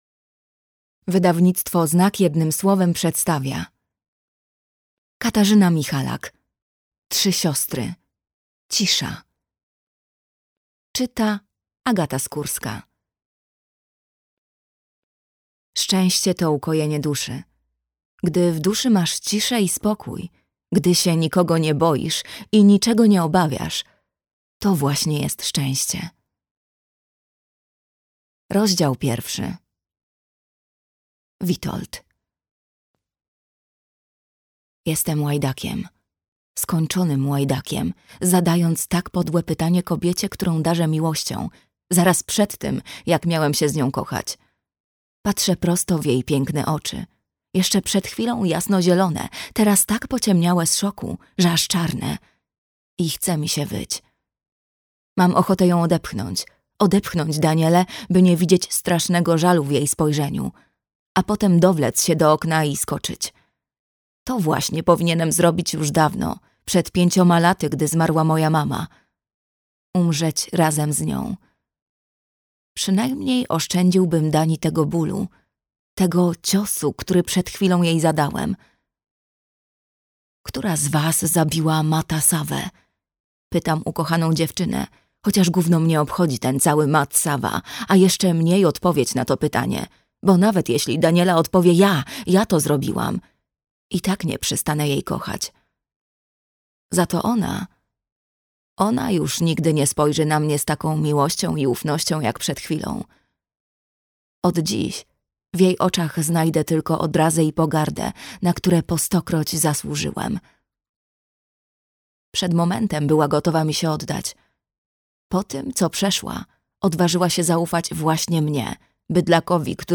Trzy siostry. Cisza - Katarzyna Michalak - audiobook